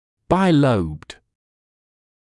[baɪ’ləubd][бай’лоубд]двудольный